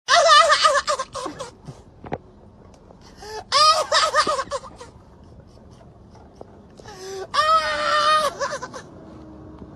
Laughing Dog Meme